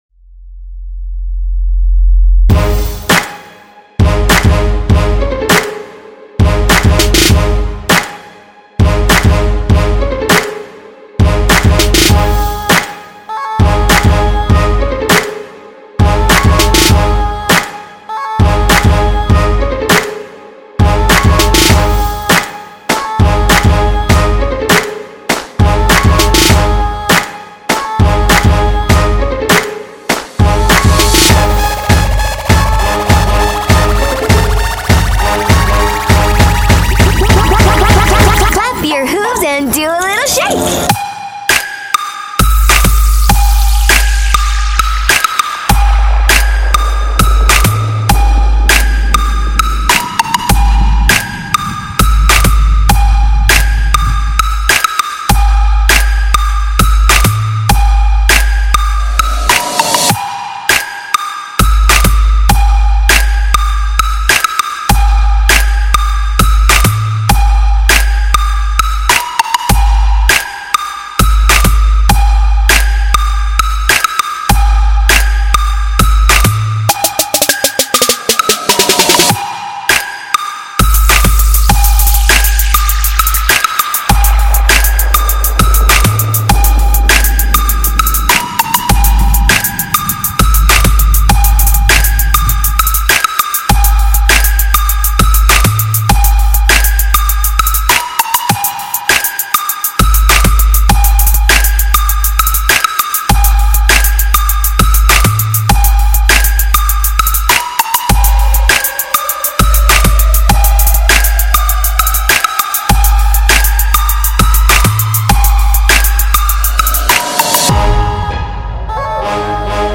So now we have a hard trap song for you!
Genre: Trap Tempo: 100bpm Key: G#